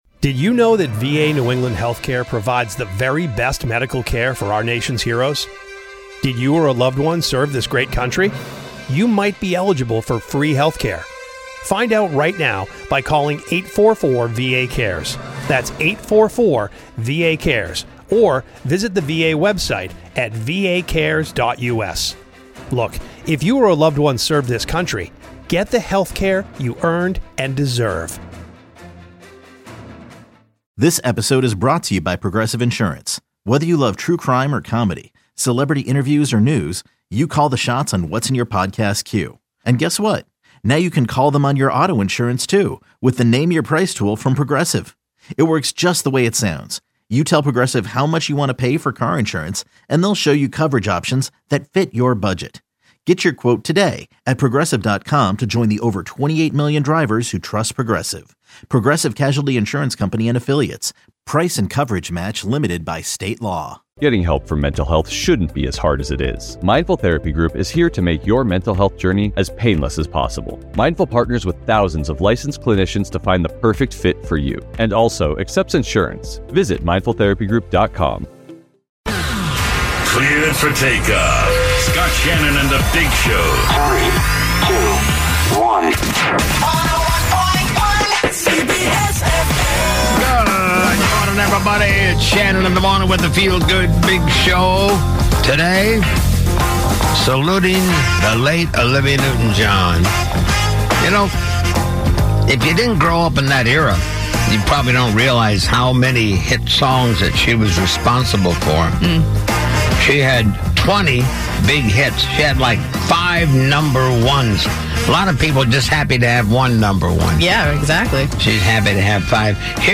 montage